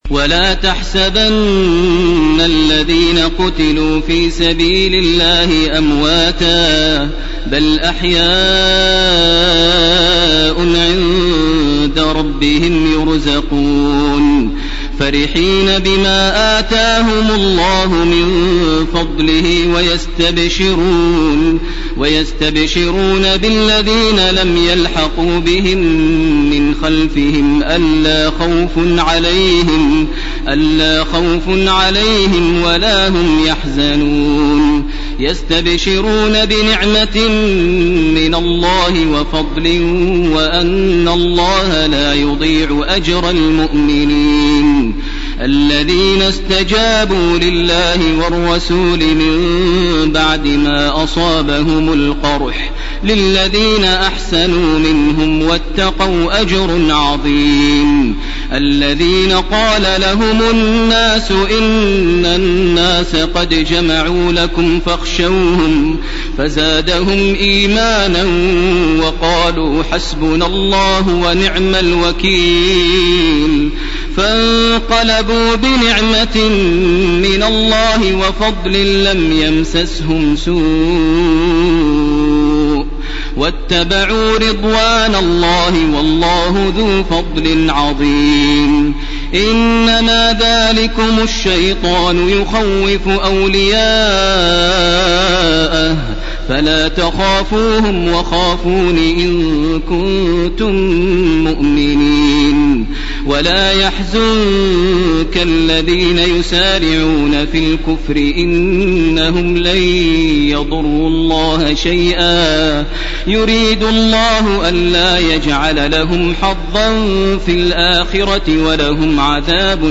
ليلة 04 رمضان لعام 1431 هـ من الآية 169 من سورة ال عمران وحتى الآية 22 من سورة النساء. > تراويح ١٤٣١ > التراويح - تلاوات ماهر المعيقلي